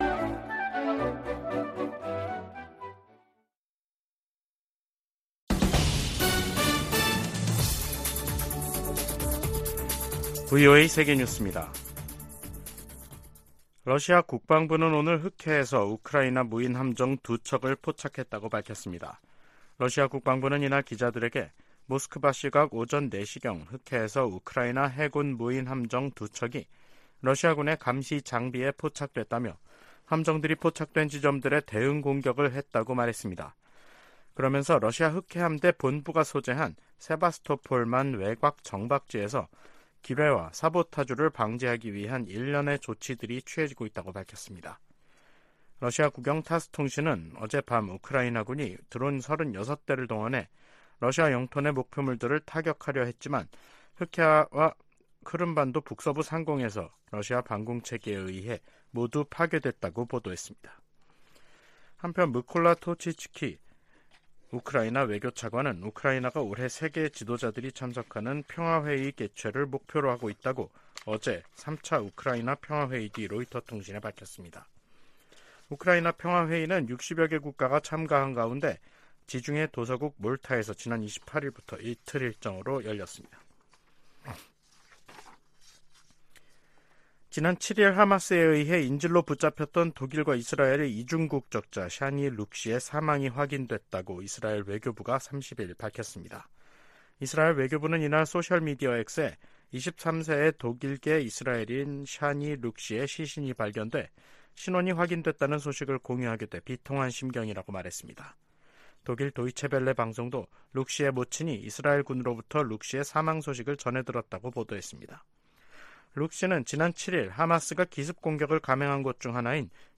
VOA 한국어 간판 뉴스 프로그램 '뉴스 투데이', 2023년 10월 30일 3부 방송입니다. 유엔총회 제1위원회가 북한의 핵무기와 대량살상무기 폐기를 촉구하는 내용이 포함된 결의안 30호를 통과시키고 본회의에 상정했습니다. 하마스가 이스라엘 공격에 북한제 대전차무기를 사용하고 있다고 중동문제 전문가가 말했습니다. 미 국무부는 북한과의 무기 거래를 부인한 러시아의 주장을 일축하고, 거래 사실을 계속 폭로할 것이라고 강조했습니다.